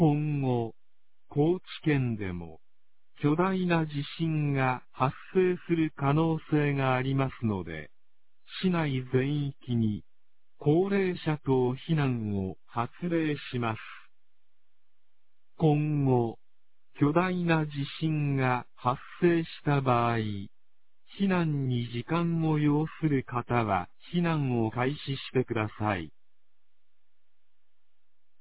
放送音声
2024年08月08日 21時32分に、南国市より放送がありました。